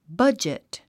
発音
bʌ’dʒit　バァジット